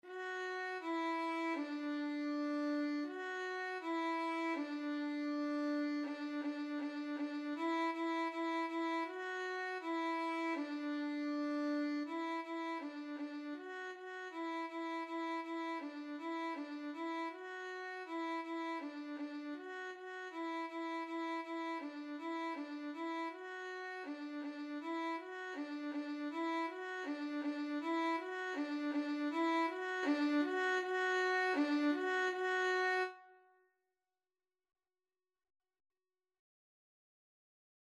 2/4 (View more 2/4 Music)
D5-F#5
Beginners Level: Recommended for Beginners
Violin  (View more Beginners Violin Music)
Classical (View more Classical Violin Music)